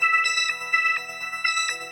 SaS_MovingPad04_125-E.wav